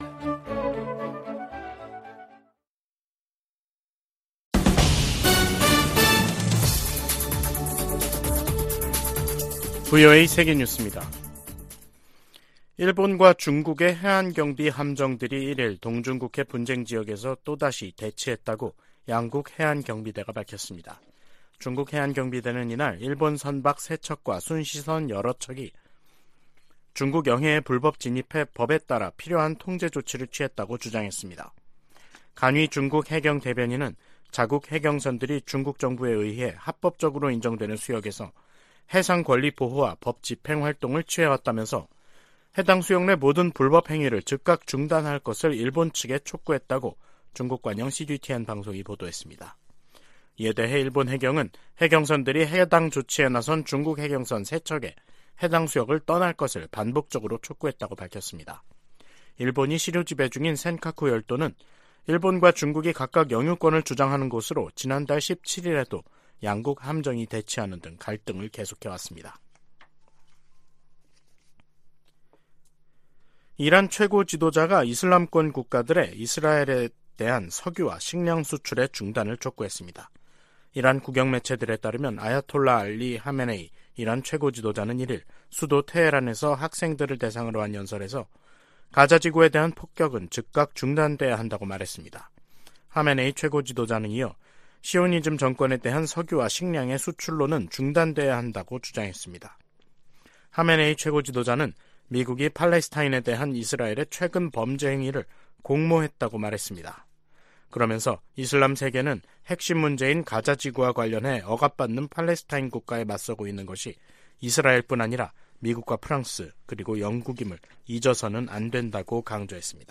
VOA 한국어 간판 뉴스 프로그램 '뉴스 투데이', 2023년 11월 1일 3부 방송입니다. 미 국방부는 한국 정부가 9.19 남북군사합의의 효력 정지를 검토 중이라고 밝힌 데 대해 북한 위협에 대응해 한국과 계속 협력할 것이라고 밝혔습니다. 한국 국가정보원은 북한이 러시아의 기술자문을 받으면서 3차 군사정찰위성 발사 막바지 준비를 하고 있다고 밝혔습니다. 미 국토안보부장관은 북한 등 적성국 위협이 진화하고 있다고 말했습니다.